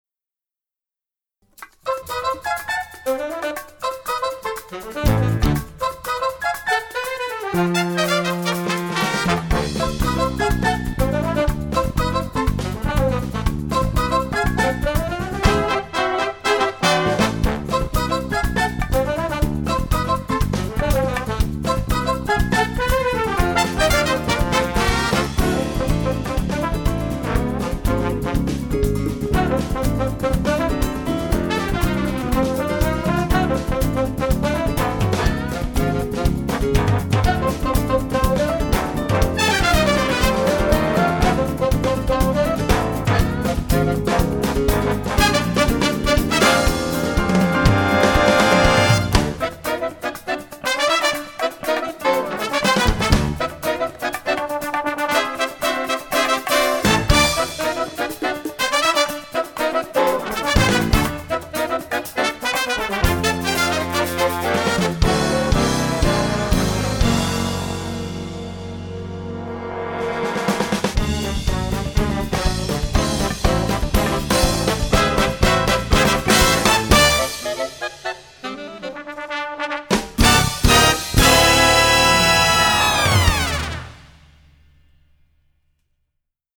jazz, latin